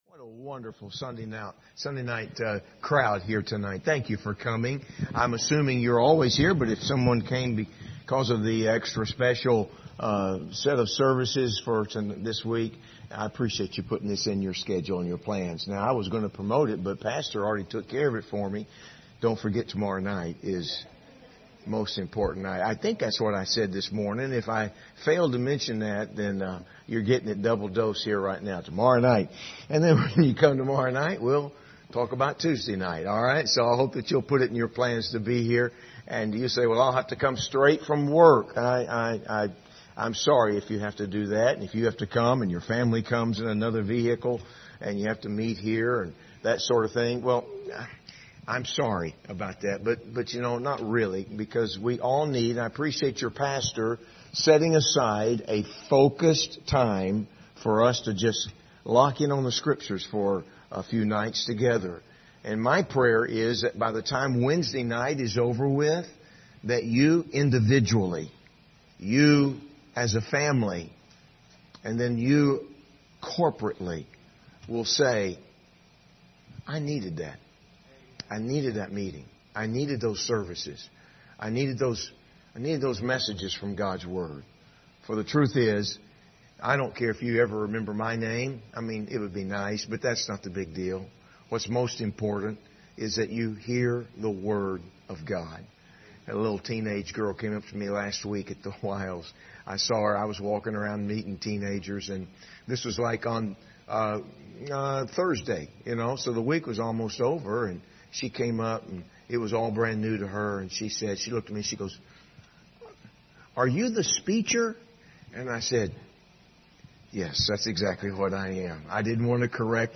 Series: 2024 Summer Revival Passage: 1 Peter 5:4-7 Service Type: Sunday Evening